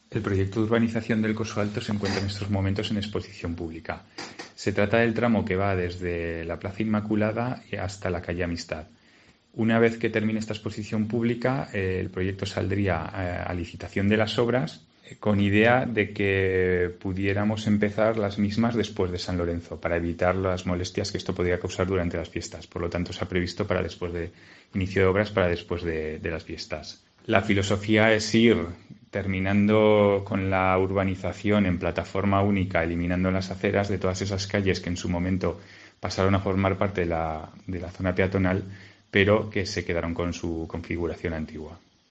El concejal de urbanismo del Ayuntaiento de Huesca, Iván Rodríguez